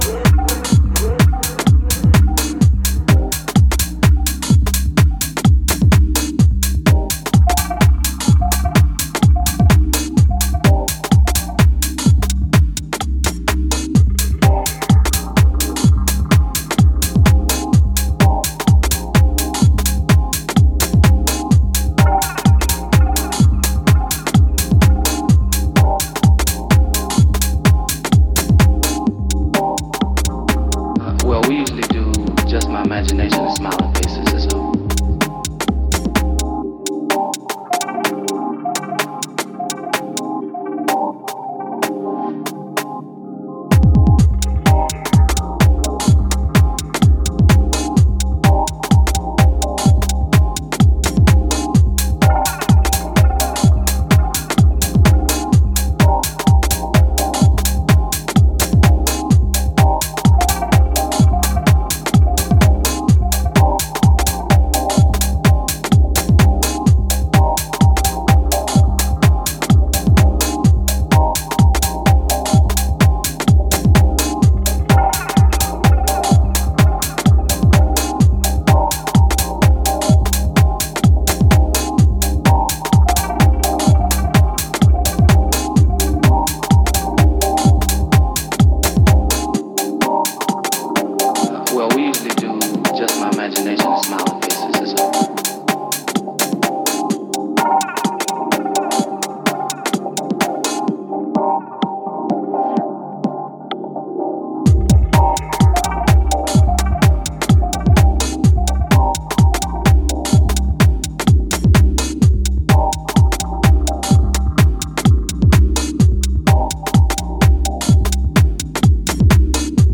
Inspired by the early white label house music releases.